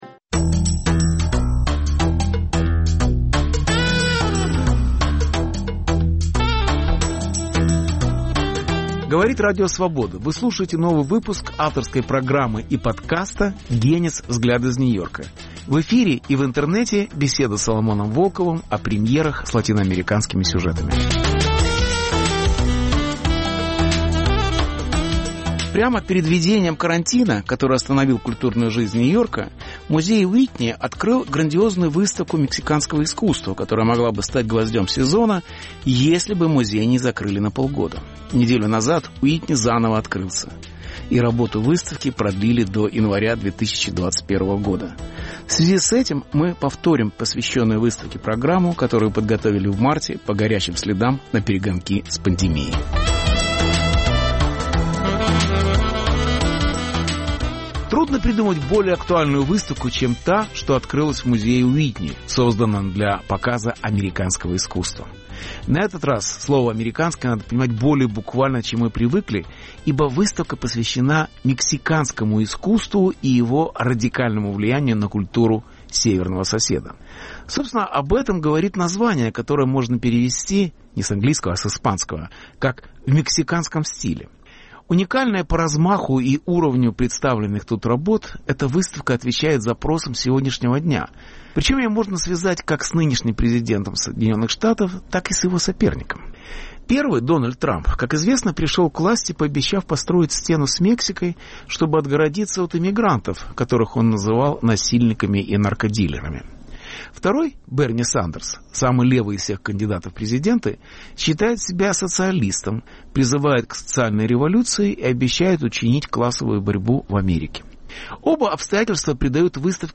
Беседа с Соломоном Волковым о великих муралистах и их революционном искусстве * Мексиканские уроки самобытности в США * Храм труда в Детройте * Как Диего Ривера писал Ленина без Сталина